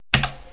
CLICK02.mp3